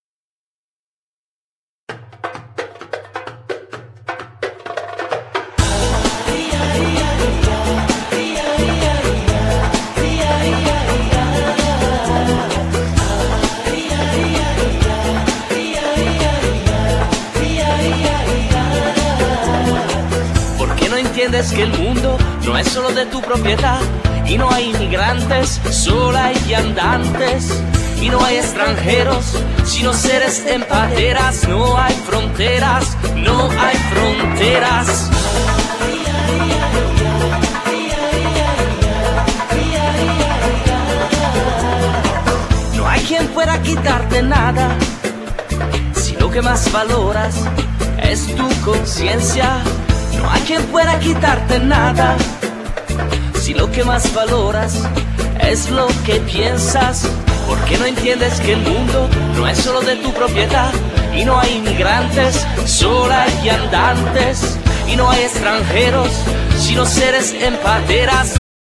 Genere: Pop
atmosfere e ritmo, conquista fin dalle prime note.